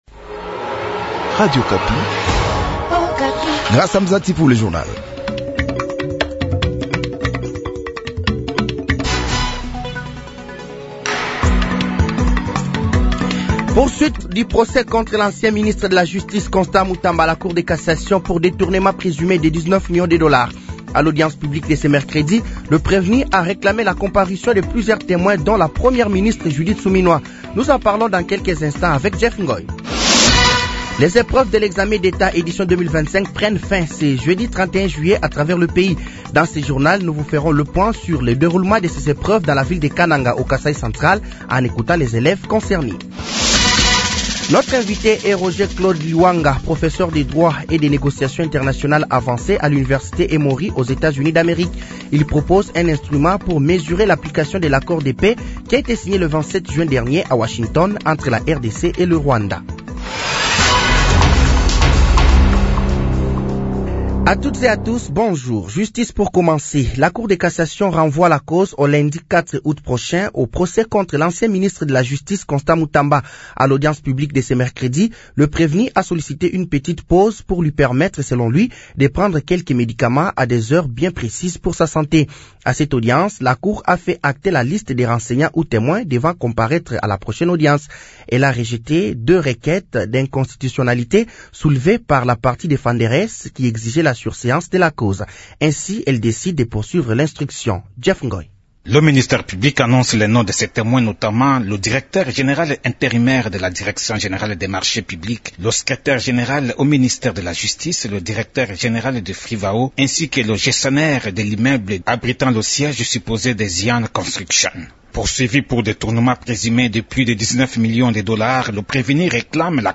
Journal français de 06h de ce jeudi 31 juillet 2025